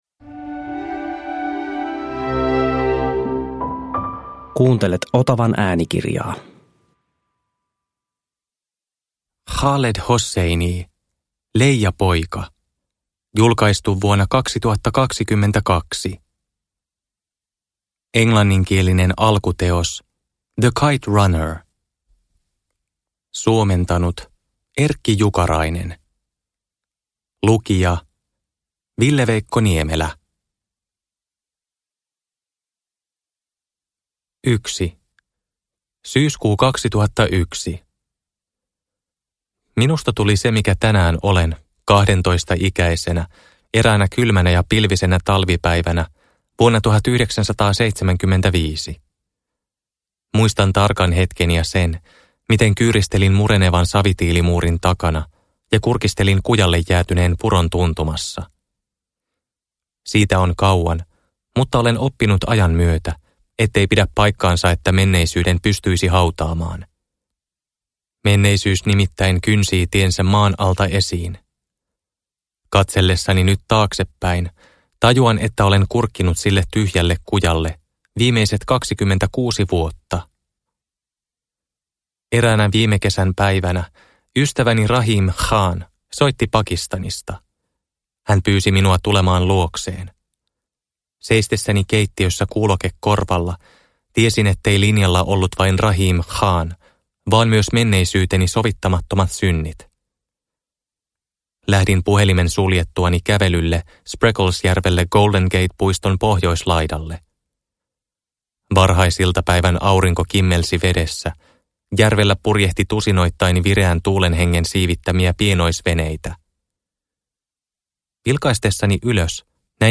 Leijapoika – Ljudbok – Laddas ner